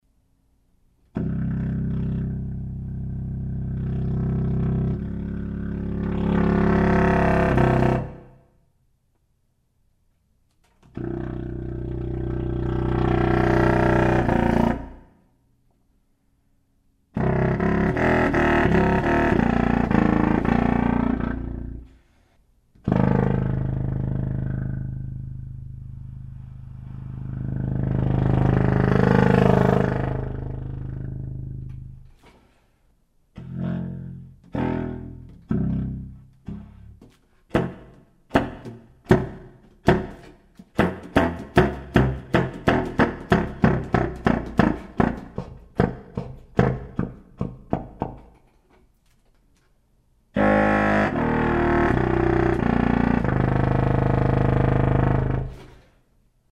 This time it's for real, and it has resulted in an incredibly rich and completely new tone color at the extreme low end of the musical spectrum!
mp3- Bb Subcontrabass saxophone 0.8MB
TubaxInBbDemo-JCE.mp3